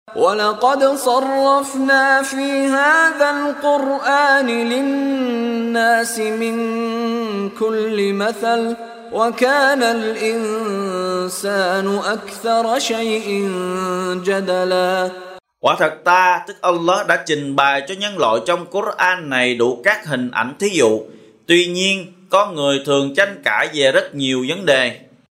Đọc ý nghĩa nội dung chương Al-Kahf bằng tiếng Việt có đính kèm giọng xướng đọc Qur’an